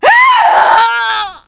death42.wav